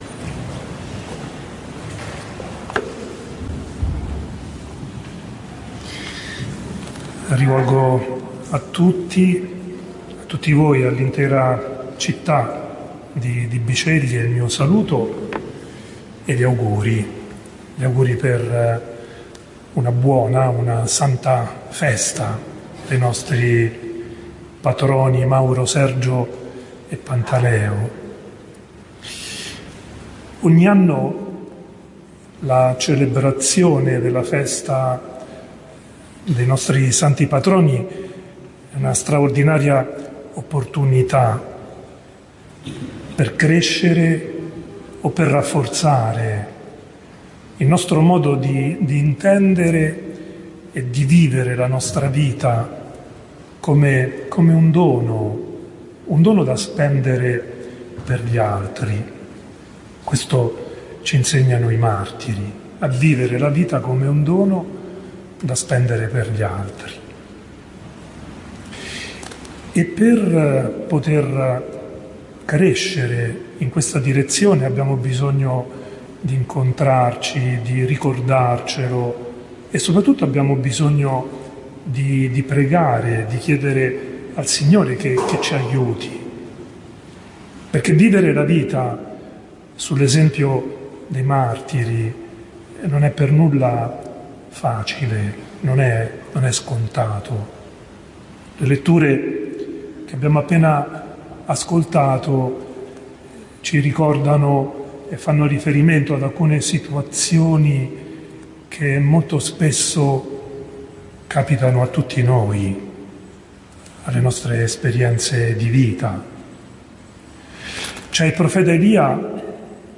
OMELIA DELL’ARCIVESCOVO IN OCCASIONE DELLA CELEBRAZONE DEI SANTI MARTIRI MAURO SERGIO E PANTALEO A BISCEGLIE PRESSO LA CONCATTEDRALE L’11 AGOSTO 2024